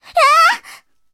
SU-26小破语音2.OGG